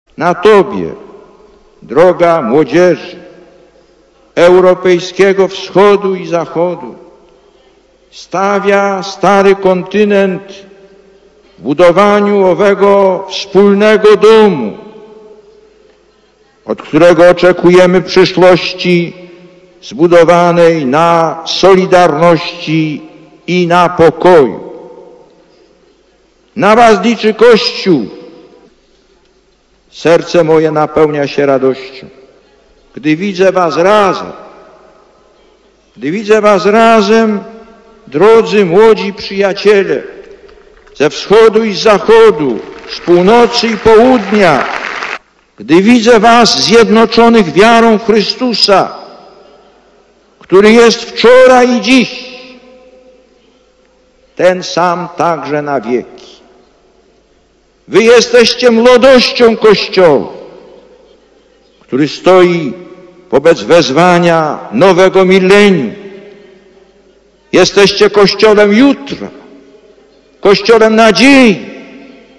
Lektor: Z homilii W czasie Mszy św. (Częstochowa, 15.08.1991 r. -